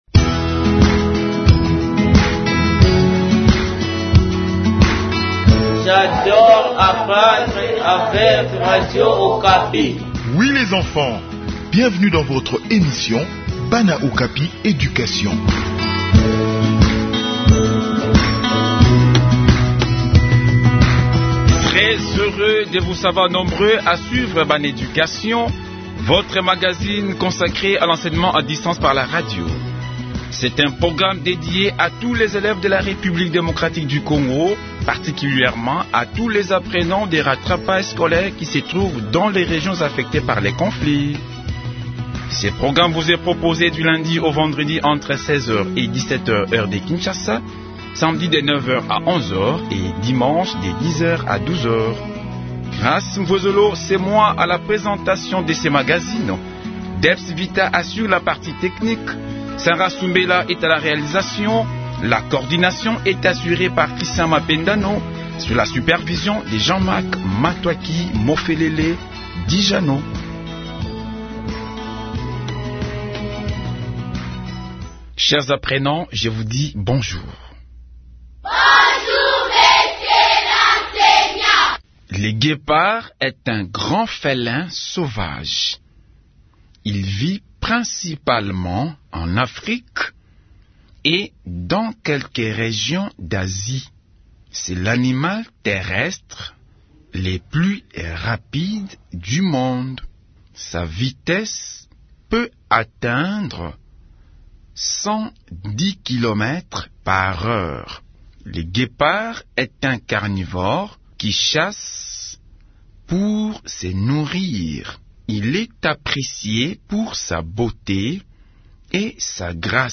Enseignement à distance : leçon de zoologie sur le guépard et la panthère